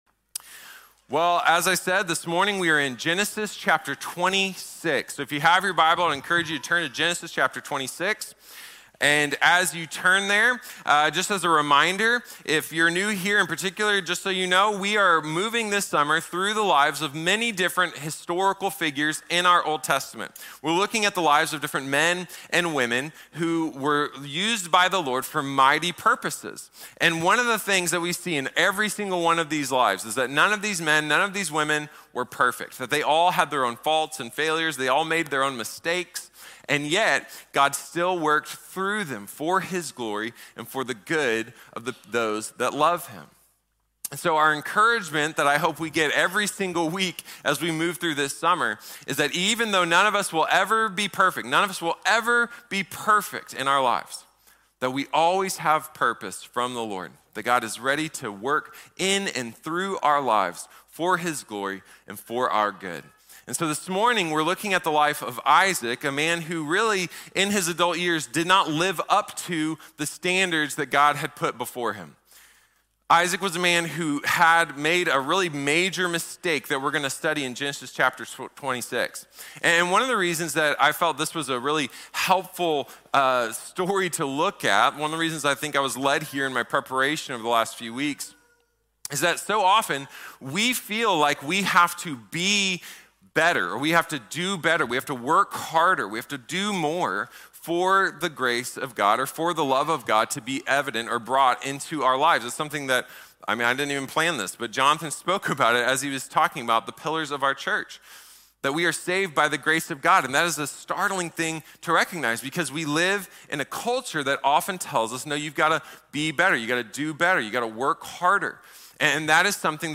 Isaac | Sermon | Grace Bible Church